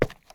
Footstep_Wood_04.wav